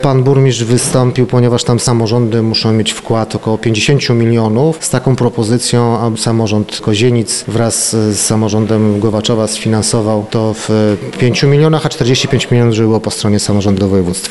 Decyzja musi być jeszcze przegłosowana przez radnych sejmiku województwa, mówi Rafał Rajkowski, wicemarszałek województwa mazowieckiego: